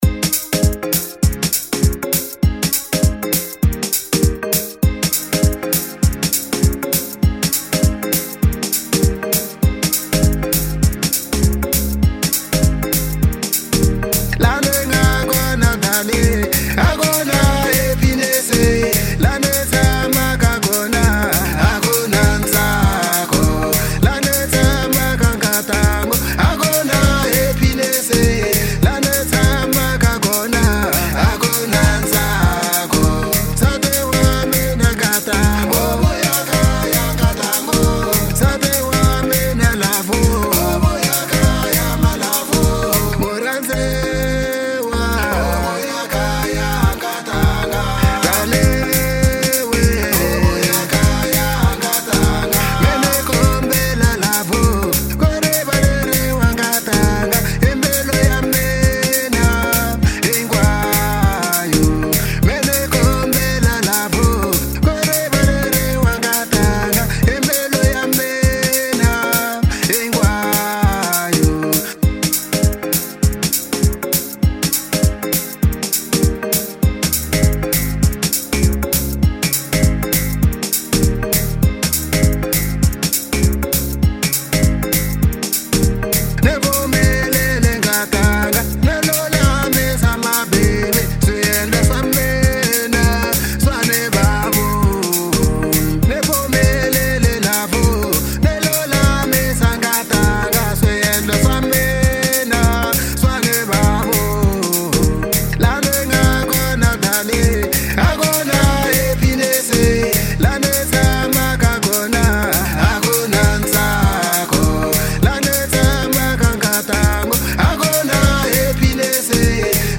04:31 Genre : African Disco Size